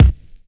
Kick (22).wav